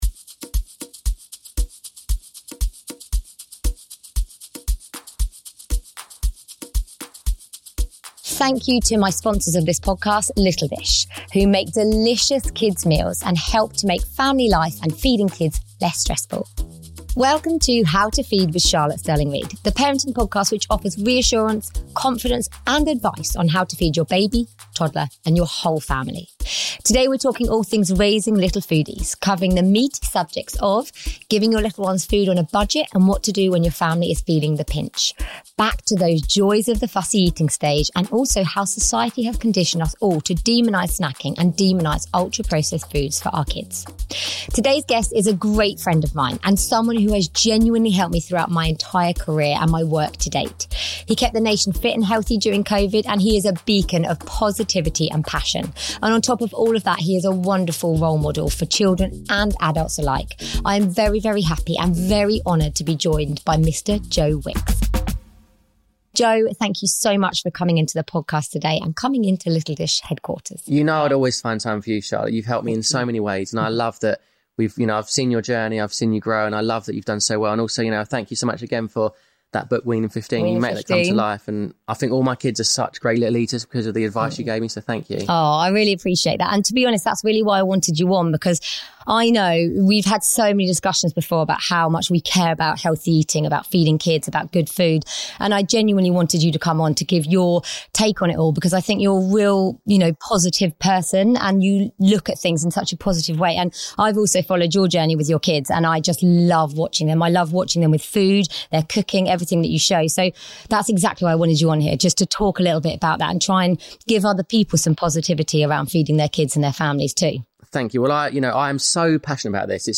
This podcast is sponsored by Little Dish and recorded at their HQ.